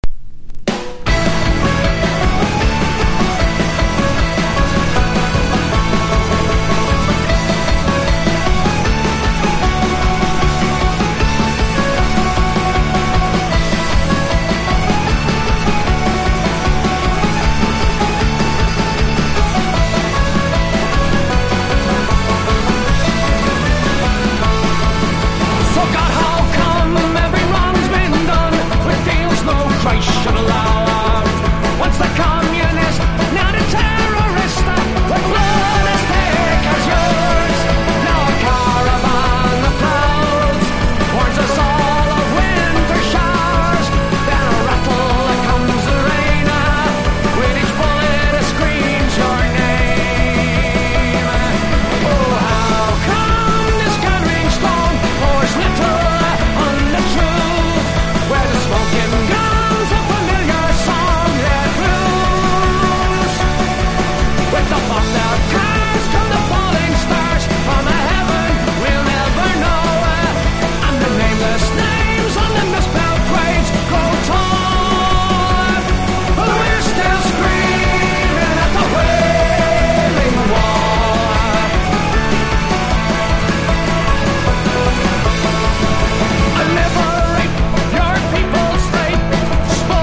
HARDCORE# 90’s ROCK